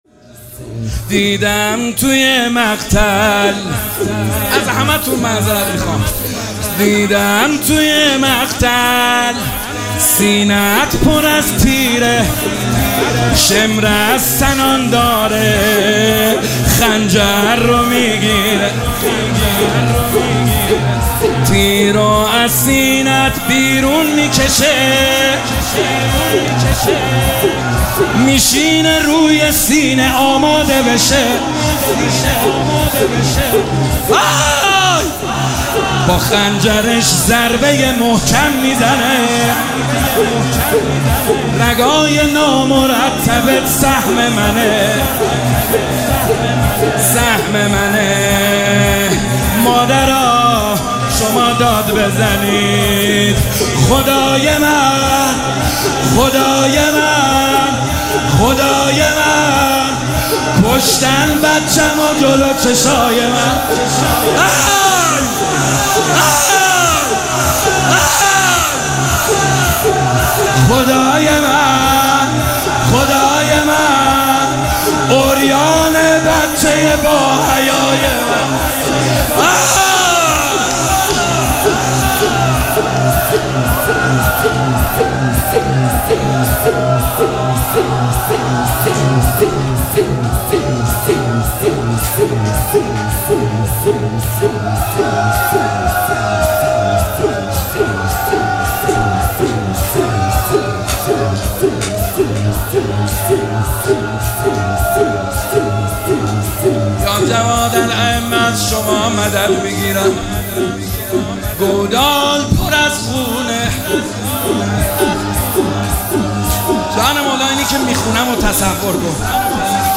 مداحی به سبک زمینه اجرا شده است.